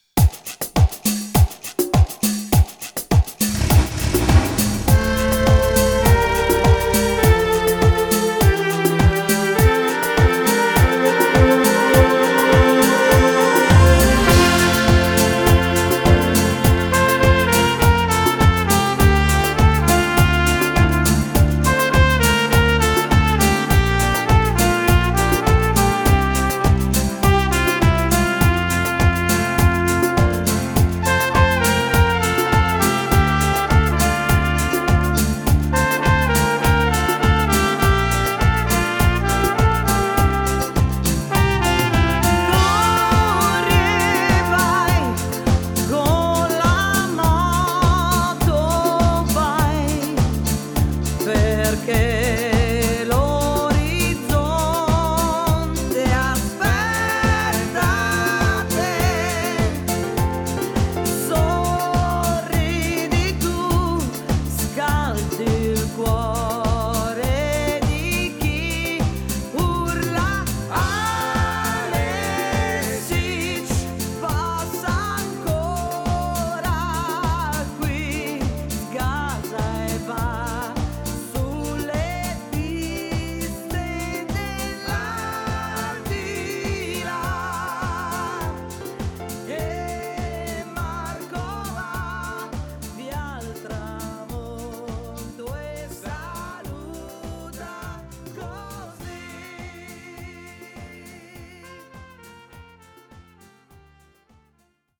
Moderato
12 brani ballabili, 10 inediti e due cover: